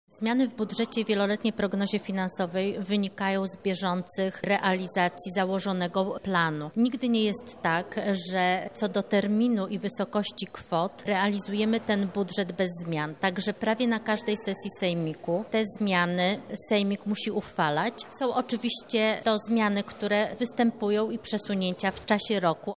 – mówi Bożena Lisowska, radna sejmiku z Platformy Obywatelskiej.